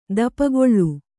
♪ dapagoḷḷu